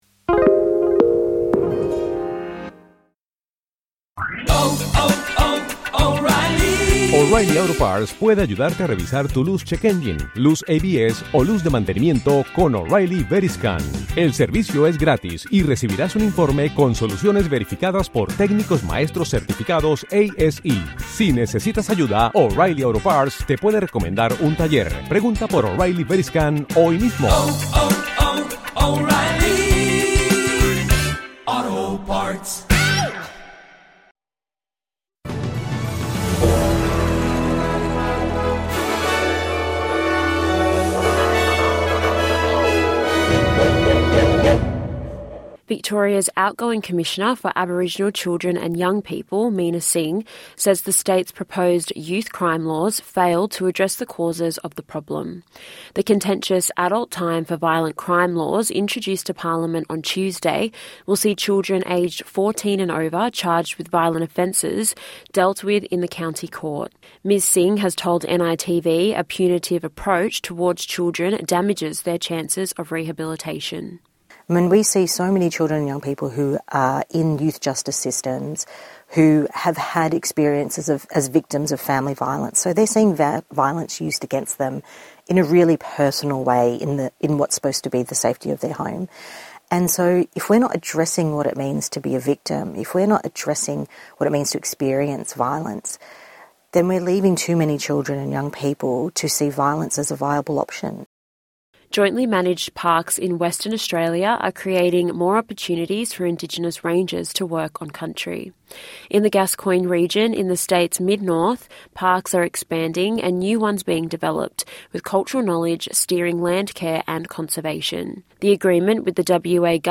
The latest national and international news for the 3rd December 2025.